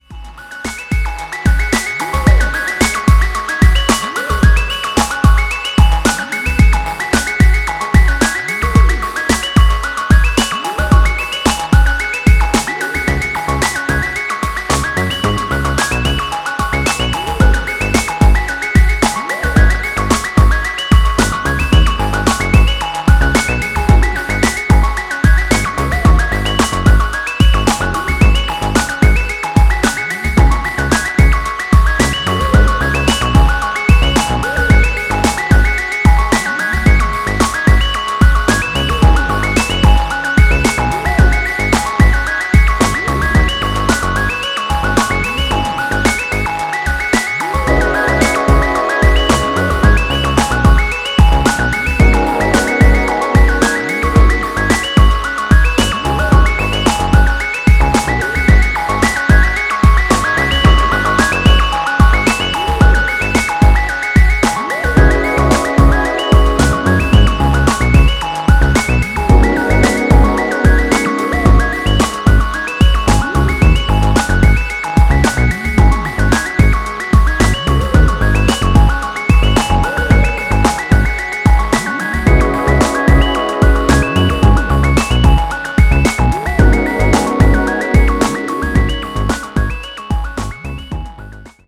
BPM110で夢見心地な情景を描くバレアリック・ビーツ